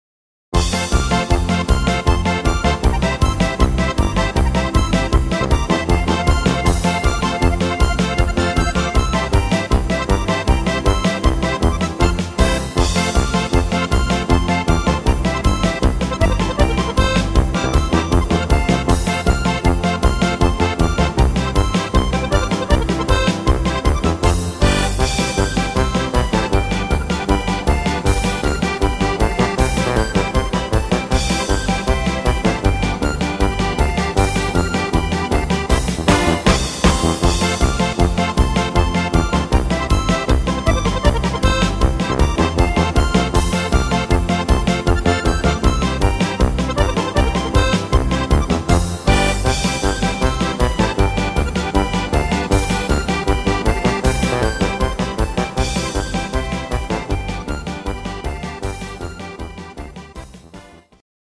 instr. steirische Harmonika